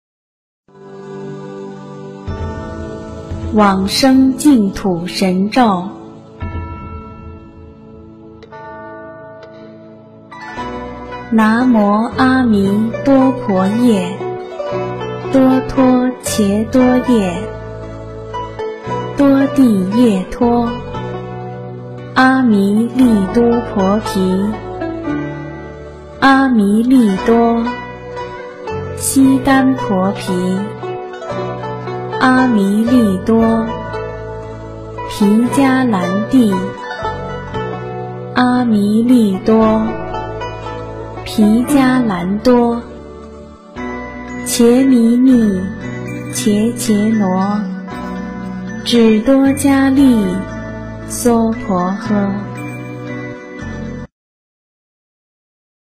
《往生咒》中文·最美大字拼音经文教念